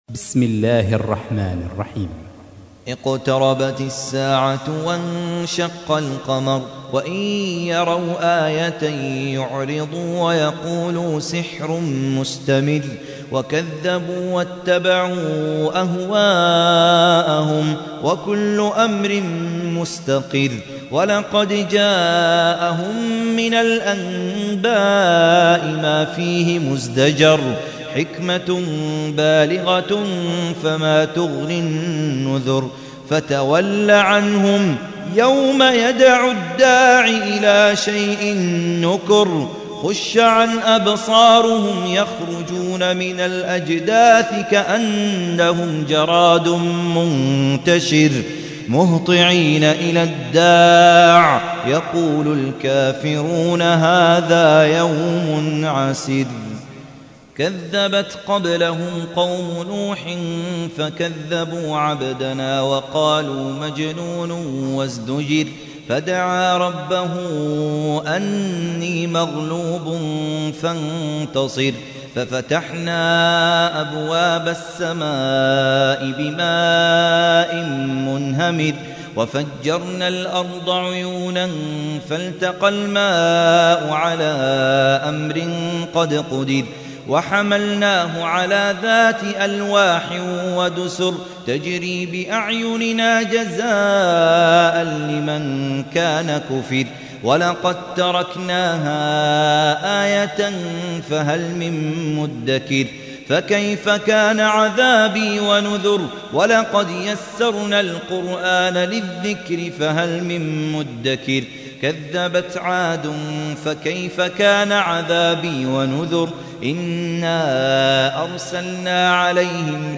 54. سورة القمر / القارئ